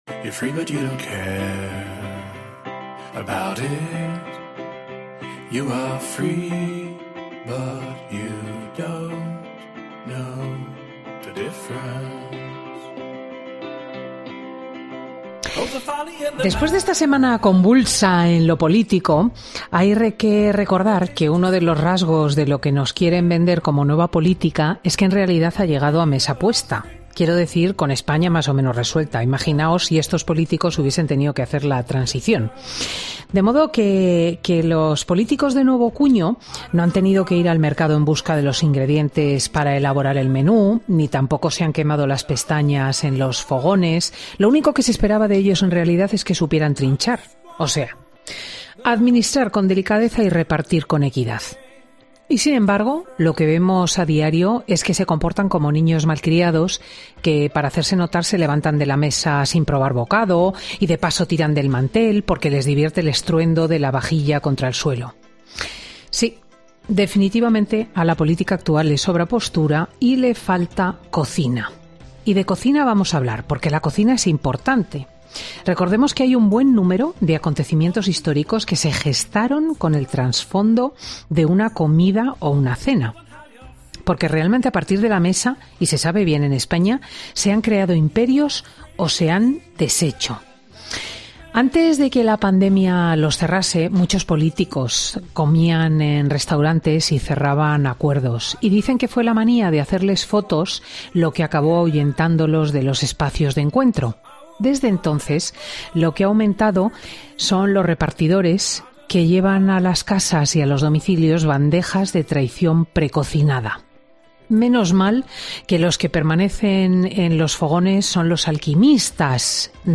Paco Roncero, prestigioso cocinero español, pasa por los micrófonos de Fin de Semana con Cristina para repasar su trayectoria profesional y contar cómo...